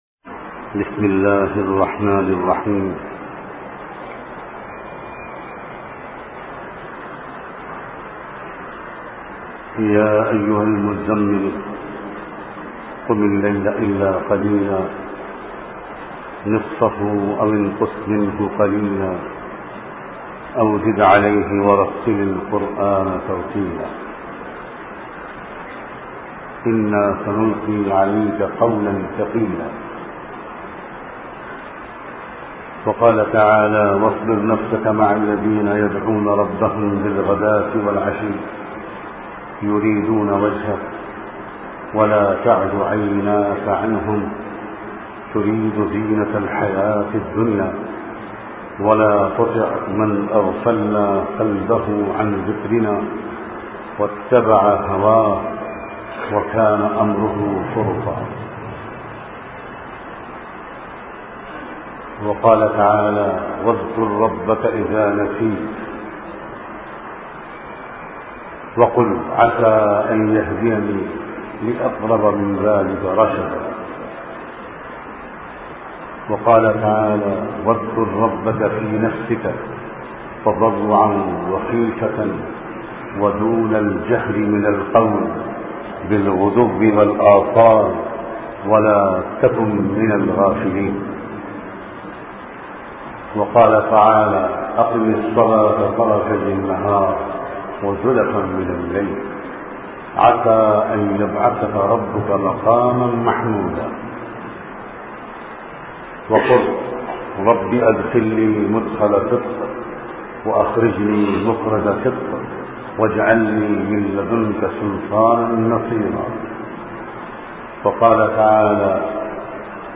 Ulama Bayan Mukammal Islah Ka Maqsad